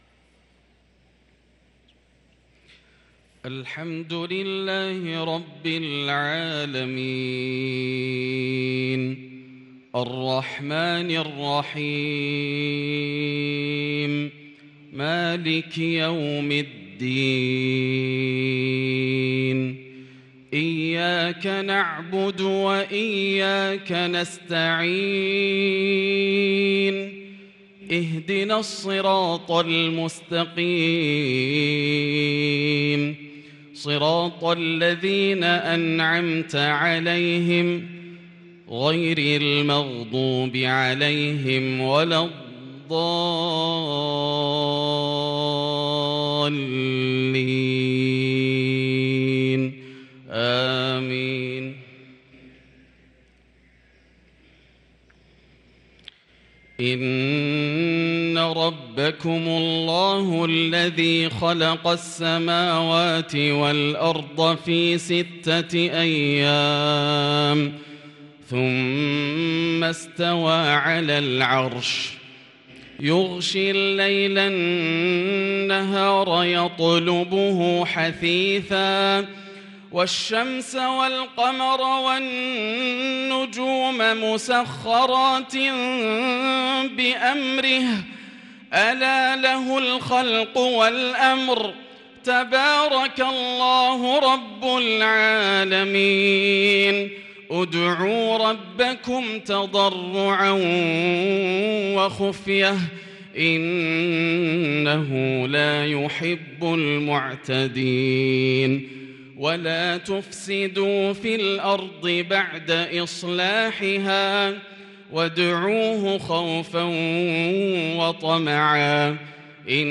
صلاة المغرب للقارئ ياسر الدوسري 17 ربيع الآخر 1444 هـ